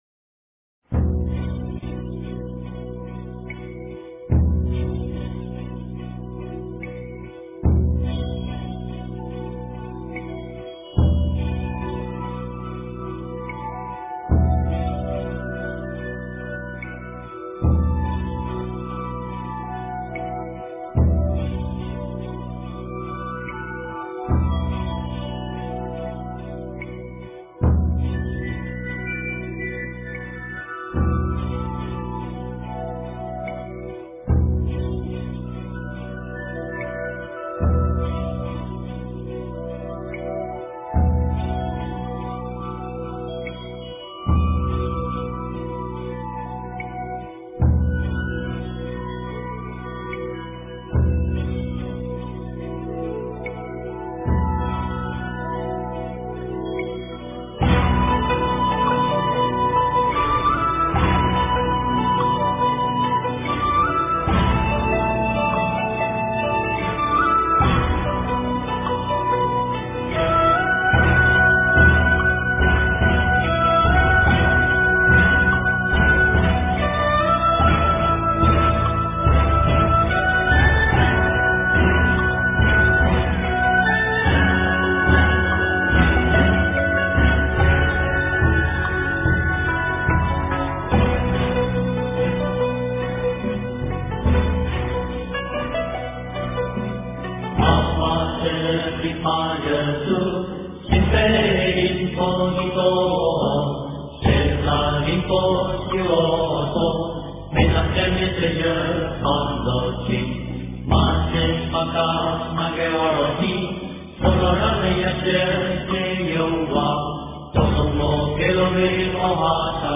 佛说圣佛母般若波罗密多心经 诵经 佛说圣佛母般若波罗密多心经--藏语版 点我： 标签: 佛音 诵经 佛教音乐 返回列表 上一篇： 一切如来心秘密全身舍利宝箧印陀罗尼 下一篇： 十一面观自在菩萨根本真言-快速版 相关文章 最快乐的事 最快乐的事--佛教音乐...